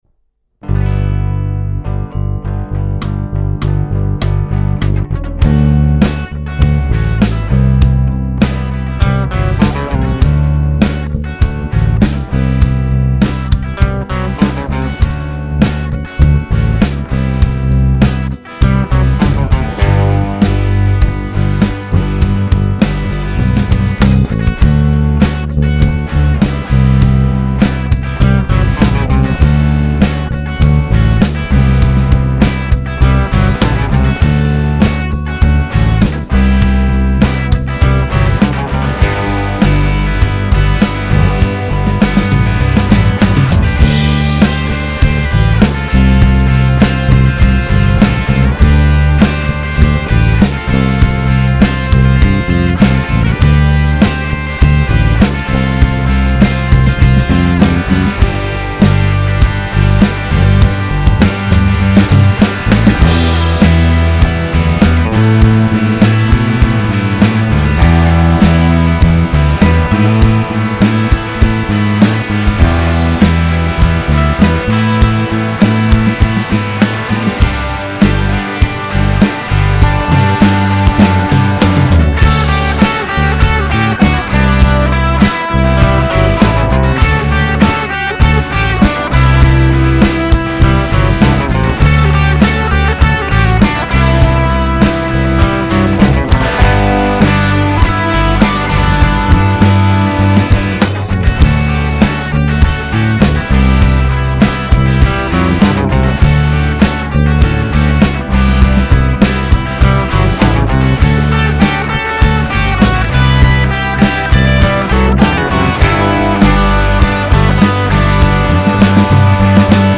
info-demo verzija instrumentalne skladbe